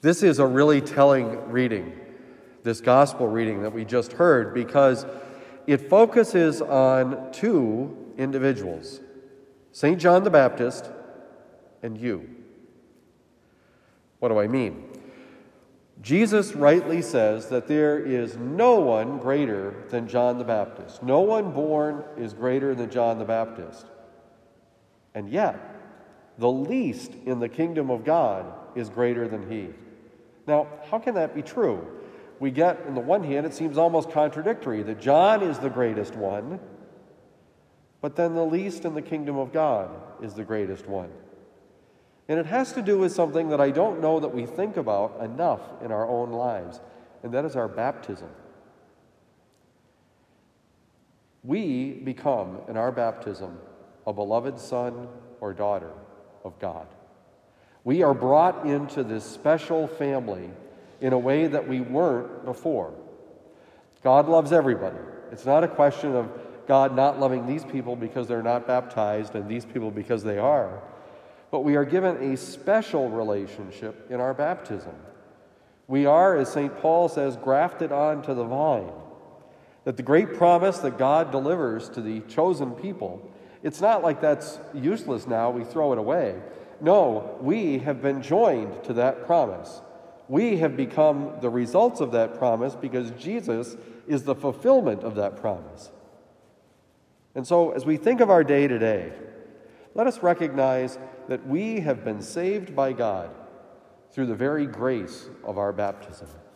Homily given at Christian Brothers College High School, Town and Country, Missouri.
Homily